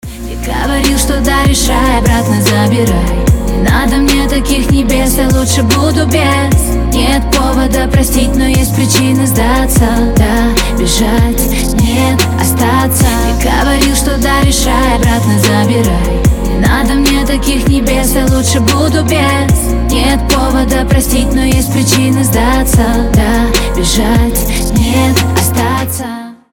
• Качество: 320, Stereo
поп
лирика
Хип-хоп